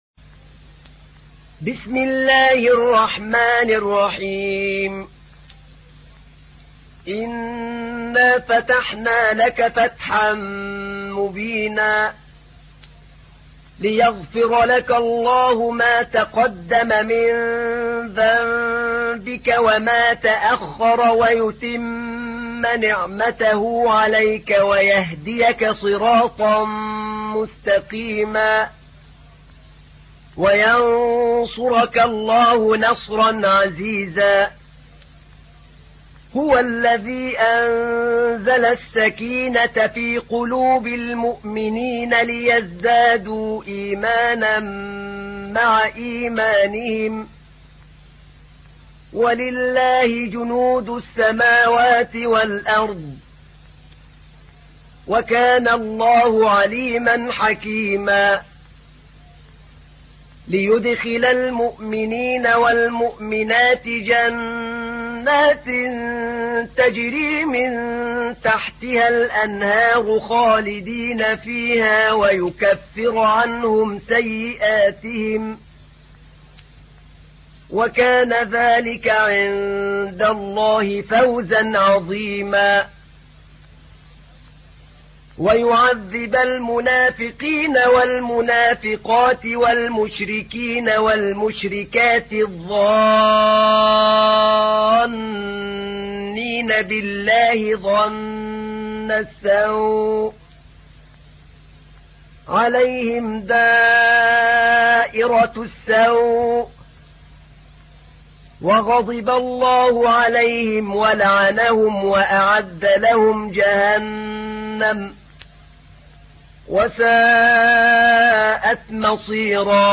تحميل : 48. سورة الفتح / القارئ أحمد نعينع / القرآن الكريم / موقع يا حسين